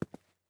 ES_Footsteps Concrete 2.wav